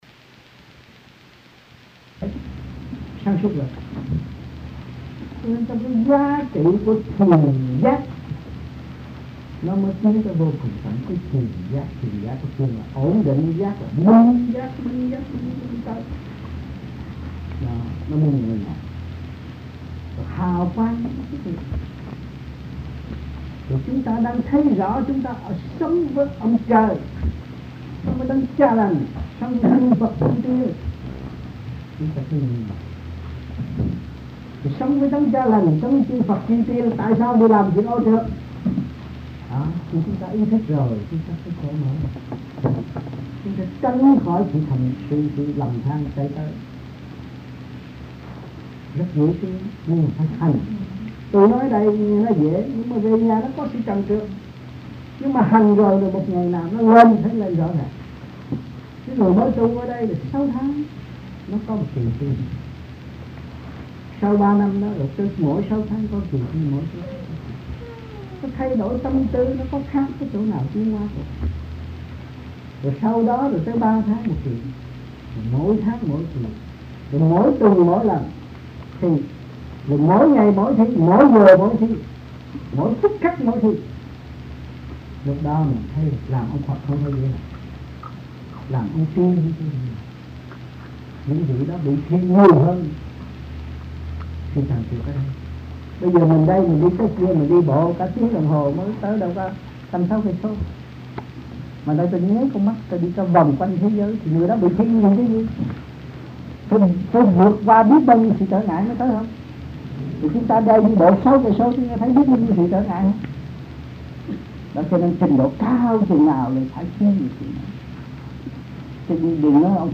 Sinh hoạt thiền đường >> wide display >> Downloads